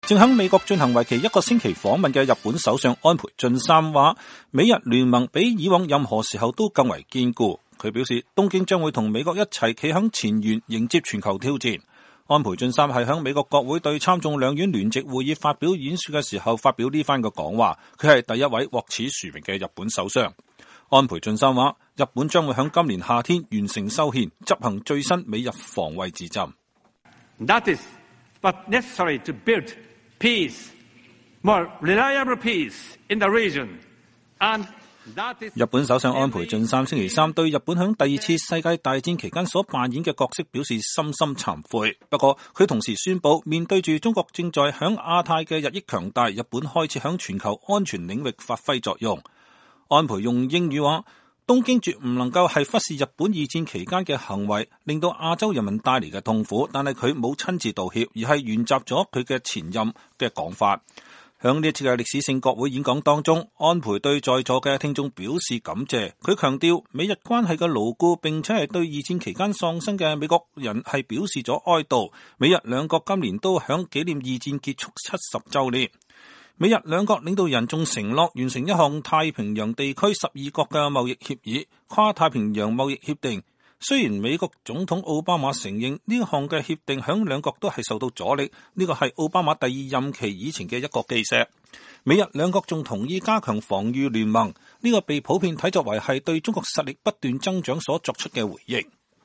2015年4月29日，日本首相安倍晉三在美國國會對參眾兩院聯席會議發表演說。
安倍用英語說，東京絕不能忽視日本二戰期間的行為給亞洲人民帶來的痛苦。